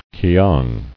[ki·ang]